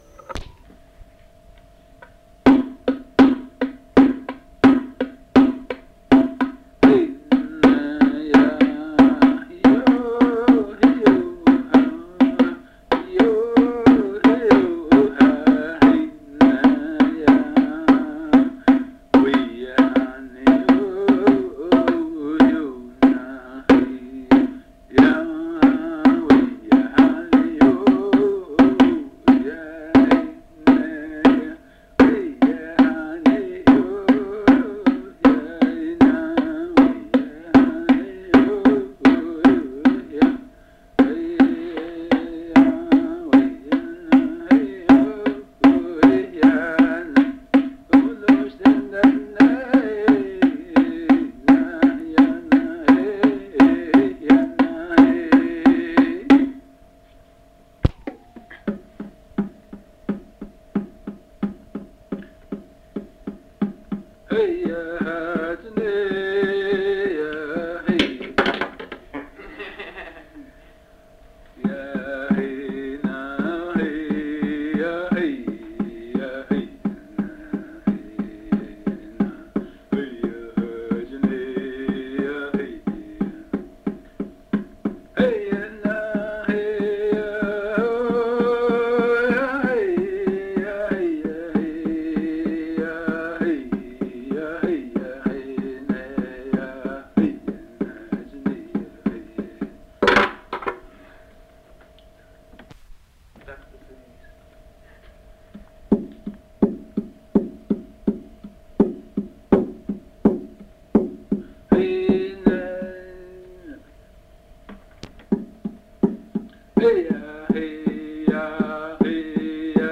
Recording Session